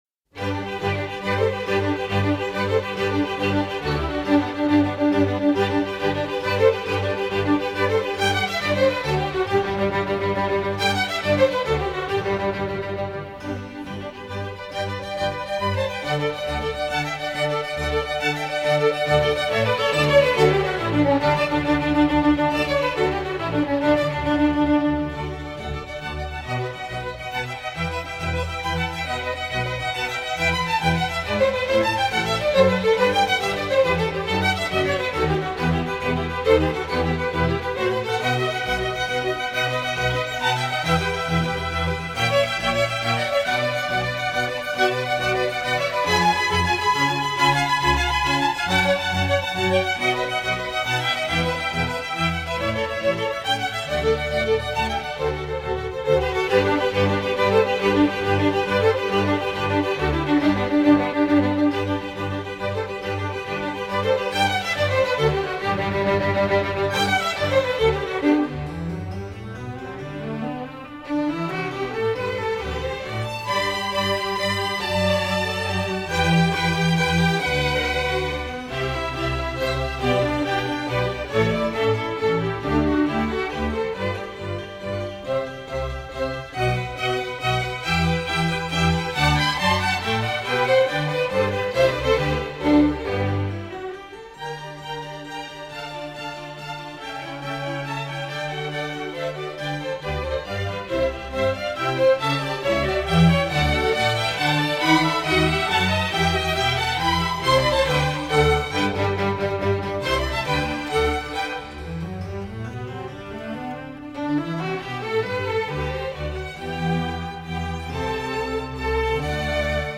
Vivaldi Concerto In G Orchestra